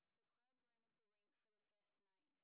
sp28_street_snr20.wav